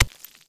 Index of /public-share/packwiz-modpacks/SMCNV-modpack/resourcepacks/SMCNV-pack/assets/minecraft/sounds/dig
stone5.ogg